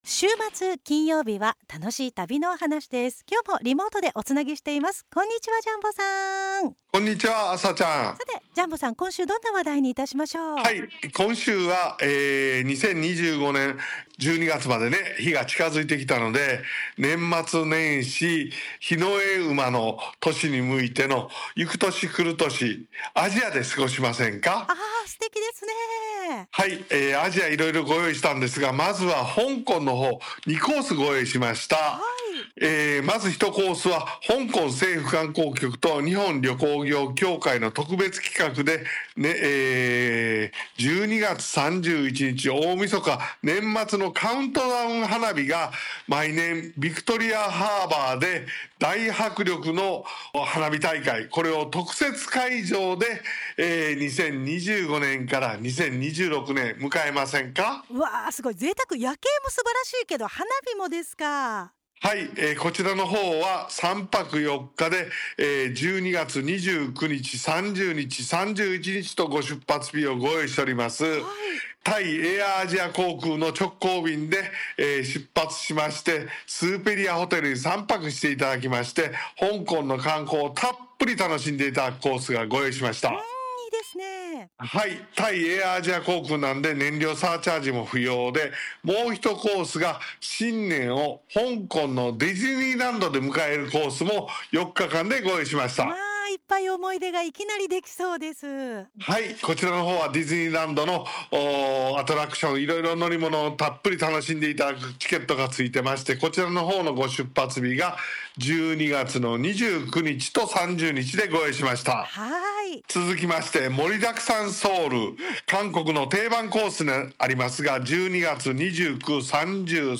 ★『《年末年始》行こうよ♪アジア（10コース）』2025年10月17日(金)ラジオ放送